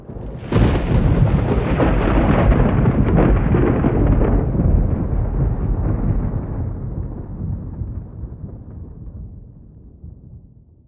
thunder4.wav